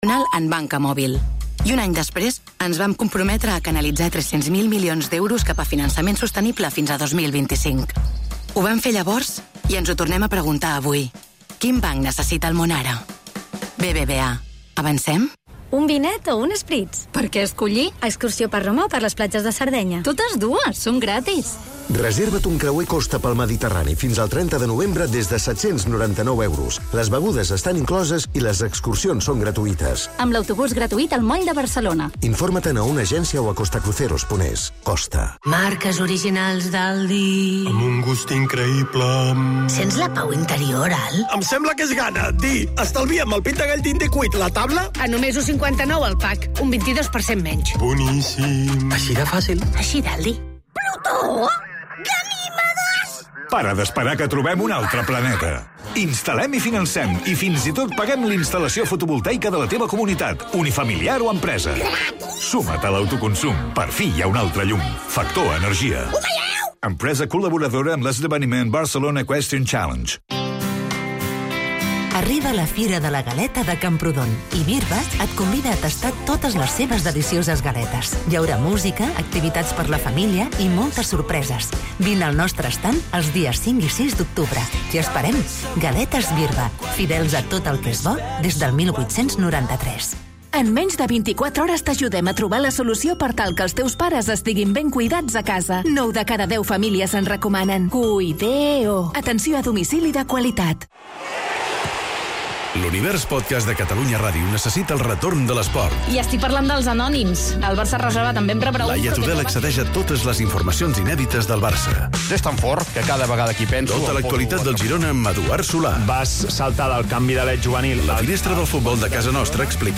Entrevistem l'alcalde de Girona, Lluc Salellas, el dia del partit de Champions del Girona.